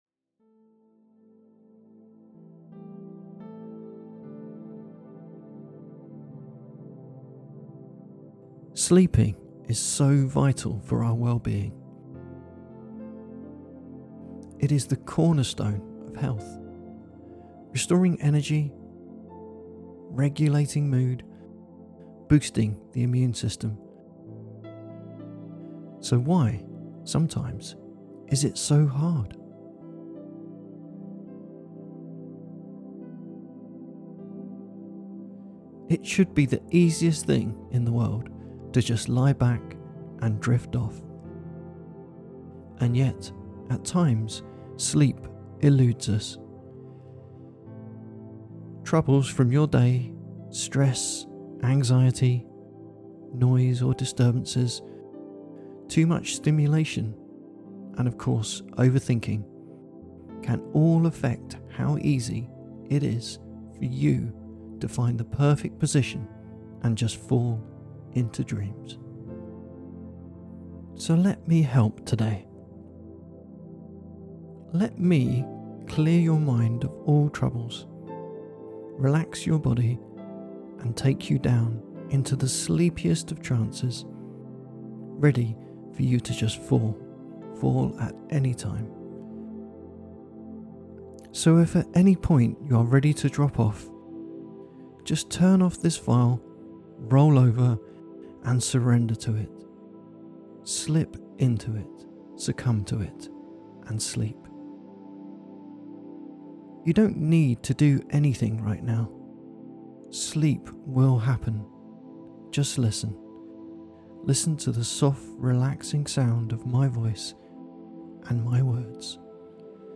Nice whispers, slow cadence, sleepy suggestions… I’m literally yawning writing this.